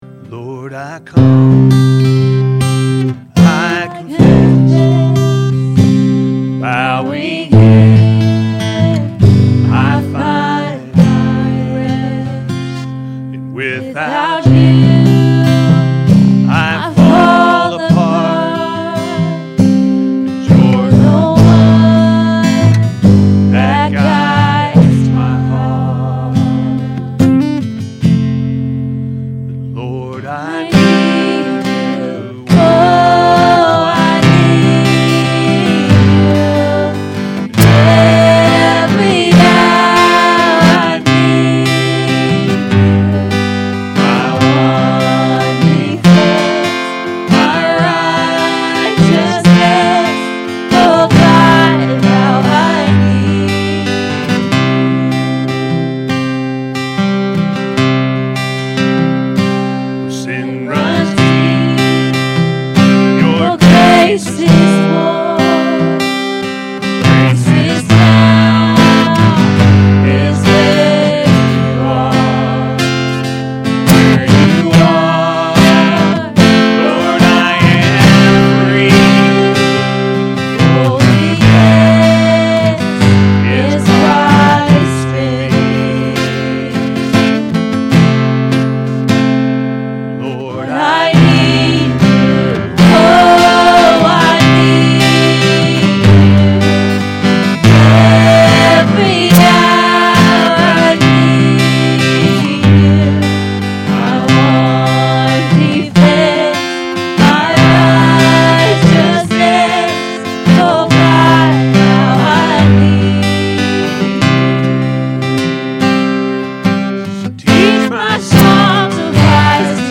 Our Commitment To Christ-A.M. Service – Anna First Church of the Nazarene